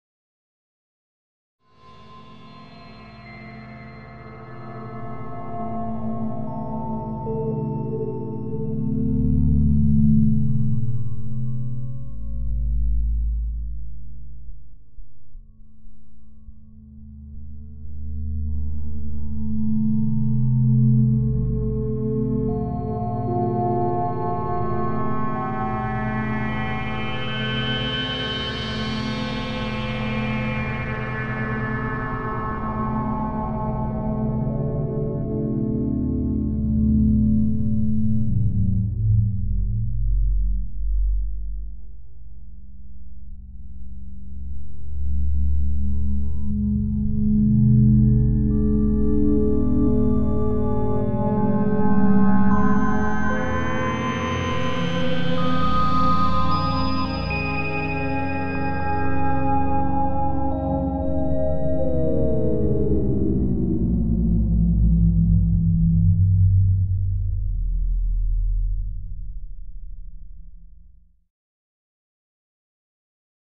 This function enables you to generate constantly evolving soundscapes. The new sequencer mute function enables you to switch on or off the sequencer strips while the sound is playing.
Soundscape.mp3